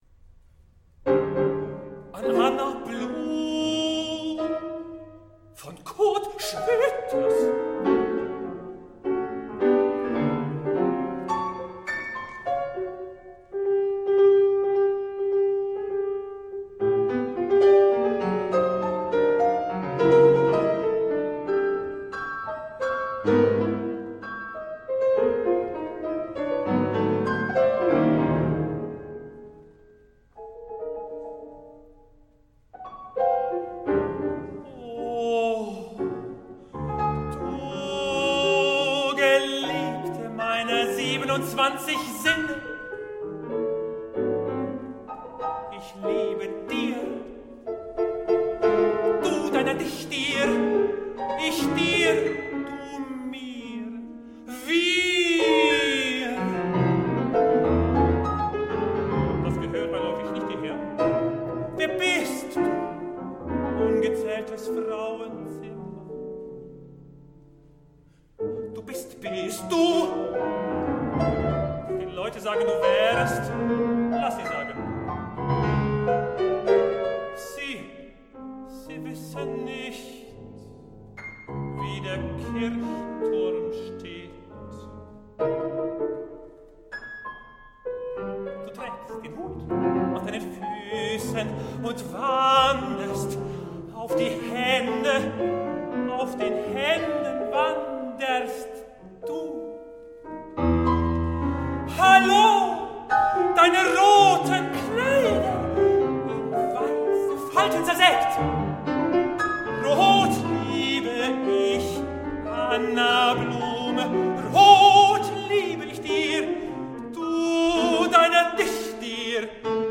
Tenor
Klavier